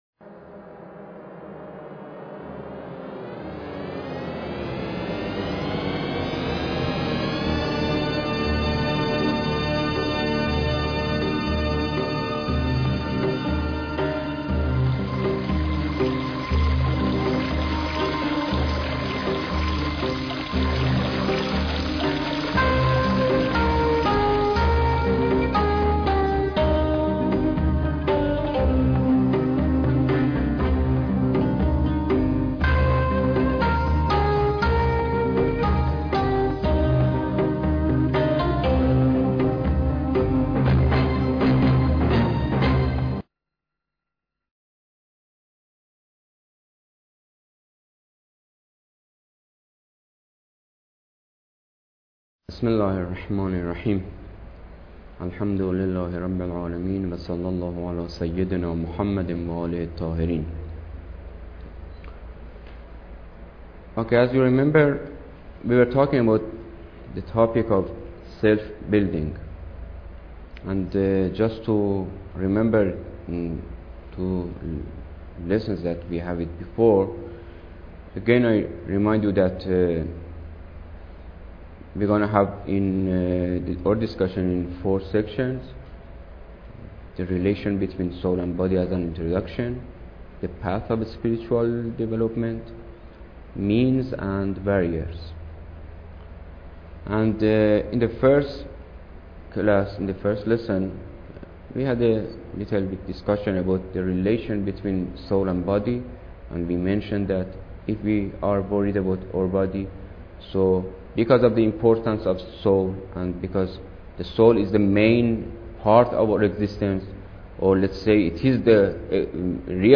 Lecture_3